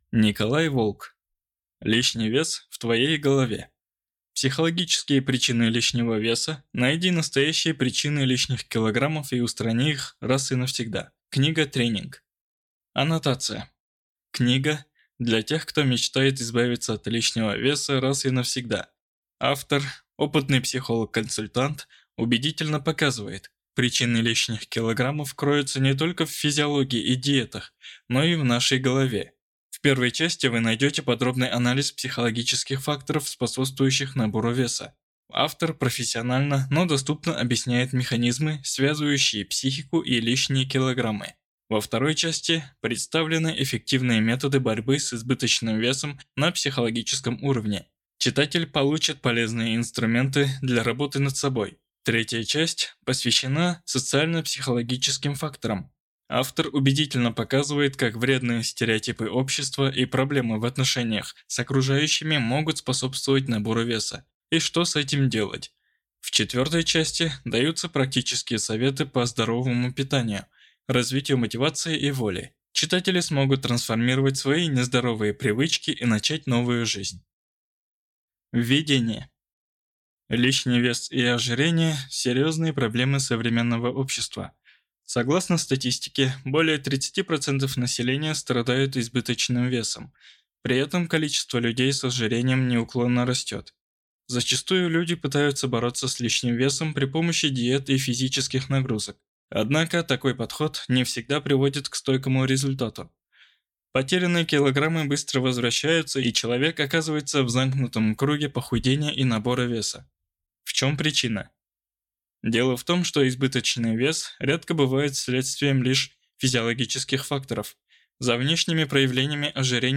Аудиокнига Лишний вес в твоей голове.